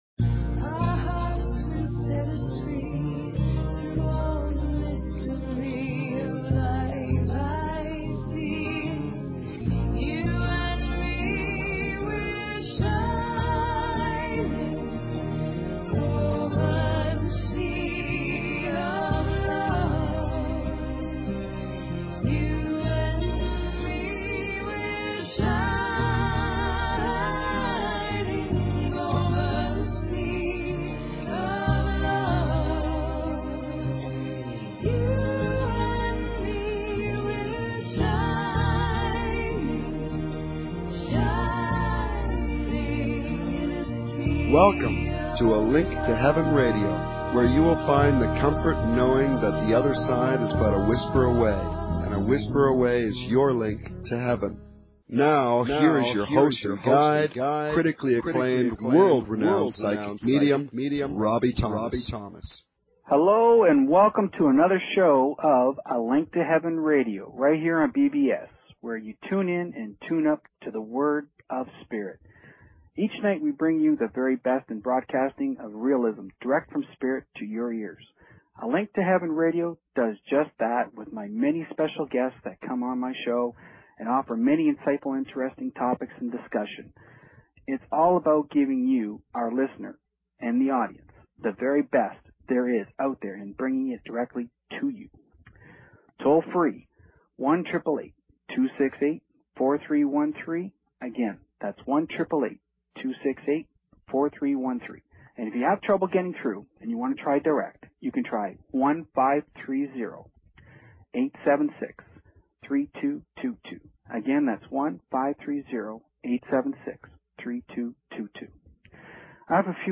Talk Show Episode, Audio Podcast, A_Link_To_Heaven and Courtesy of BBS Radio on , show guests , about , categorized as